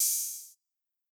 YM Open Hat 2.wav